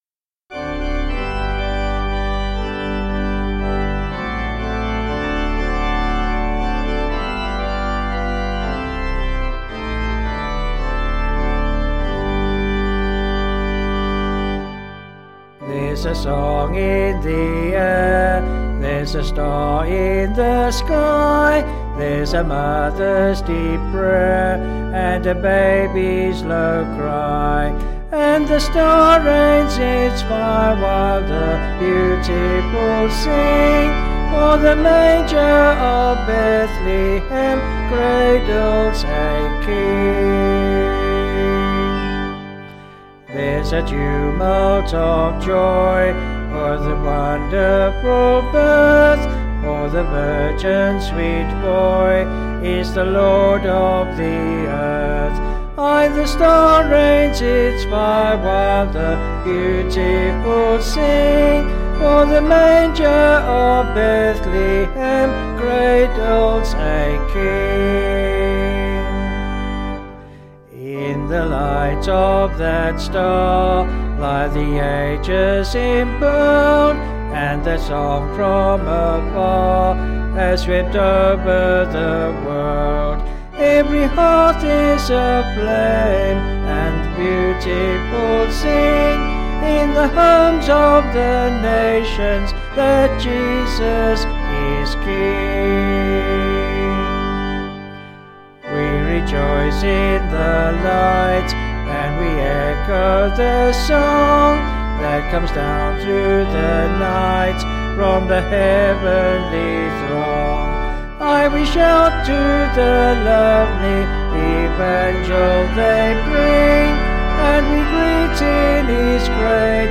Vocals and Band   263.6kb Sung Lyrics 1.8mb